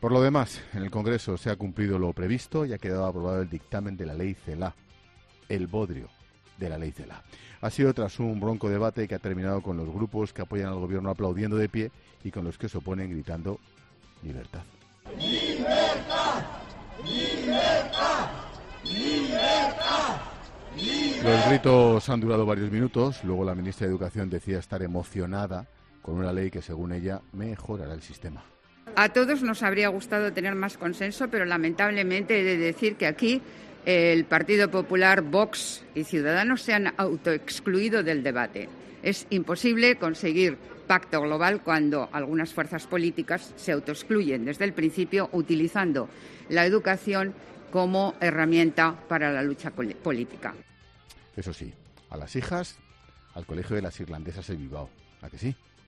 El presentador de 'La Linterna' se sorprende con el discurso de la ministra de Educación y resalta un pequeño matiz